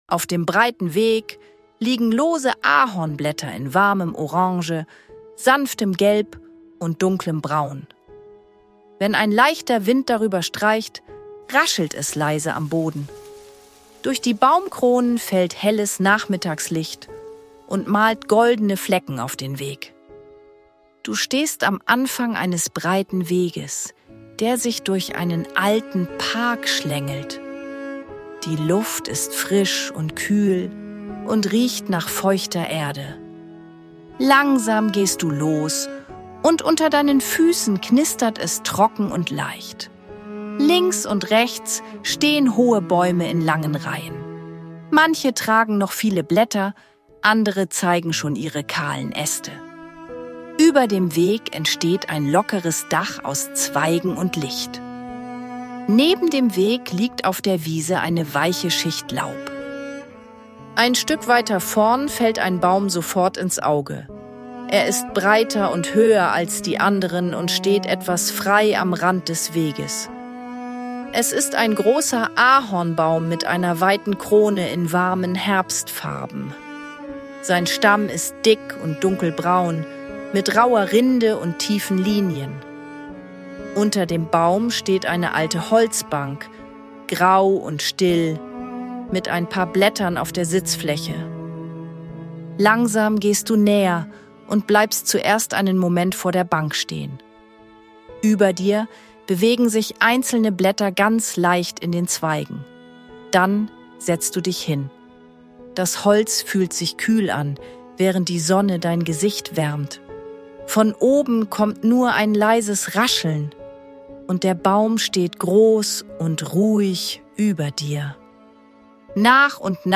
Sanfte Fantasiereisen mit leiser Hintergrundmusik – zum Malen und kreativen Entspannen